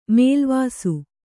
♪ mēlvāsu